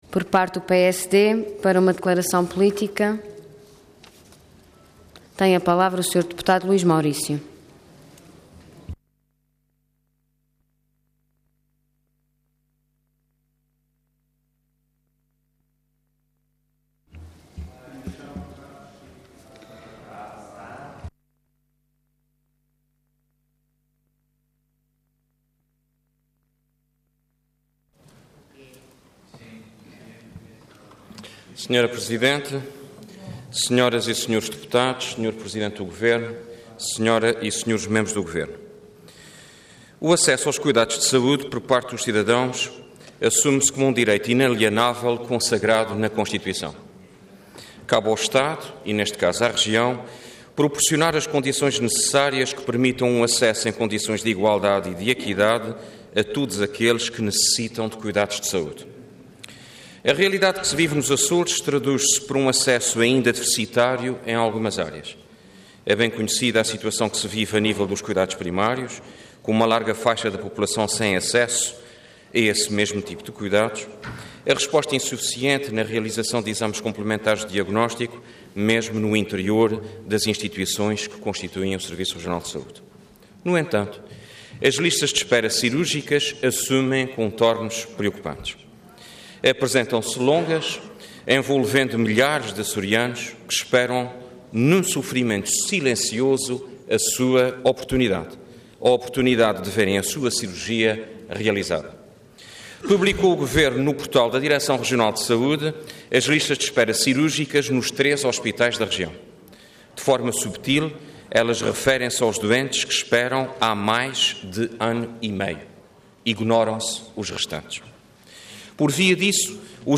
Intervenção Declaração Política Orador Luís Maurício Cargo Deputado Entidade PSD